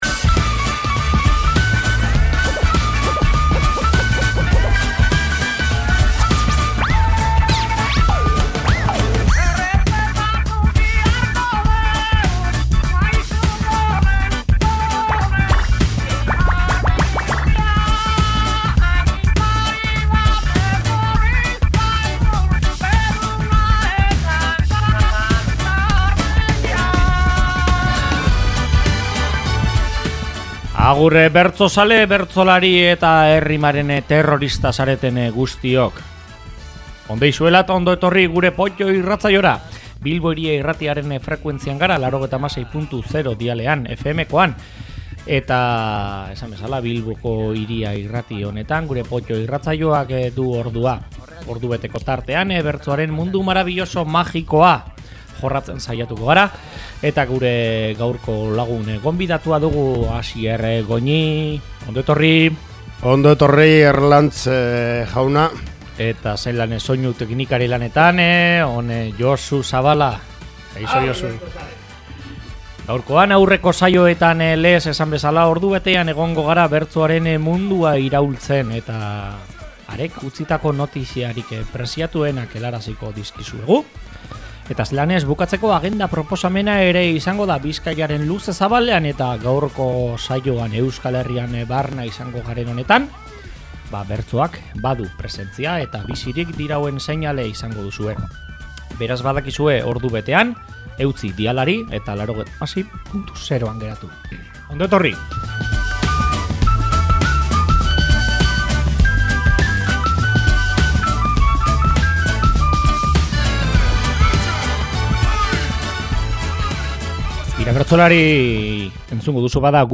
Bertso aukeratuen zerrenda ekarri dute entzuleen buru-belarrien gozagarri, beraz, piztu irratia eta… ke biba el berso!!!